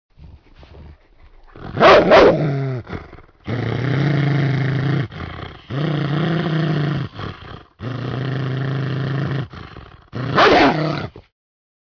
دانلود صدای سگ درنده از ساعد نیوز با لینک مستقیم و کیفیت بالا
جلوه های صوتی